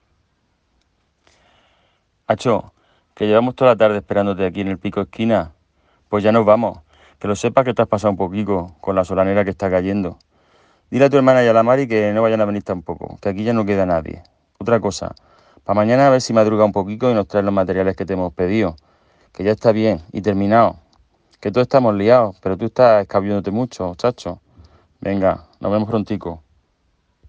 Murciano.ogg